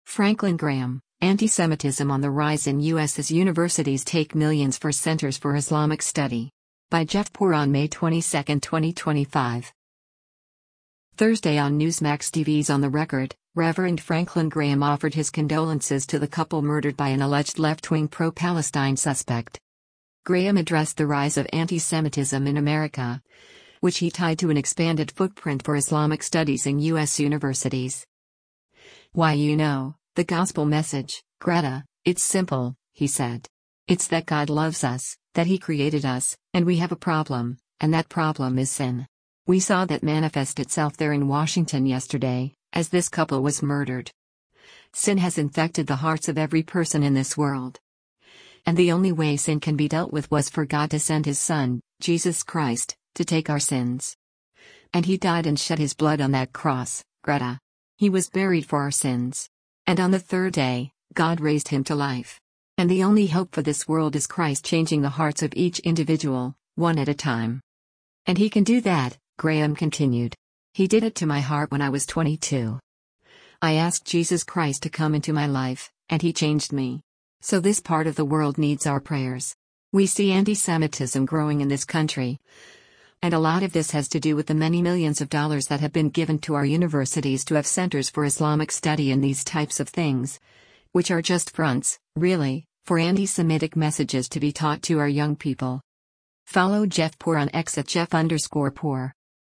Thursday on Newsmax TV’s “On the Record,” Rev. Franklin Graham offered his condolences to the couple murdered by an alleged left-wing pro-Palestine suspect.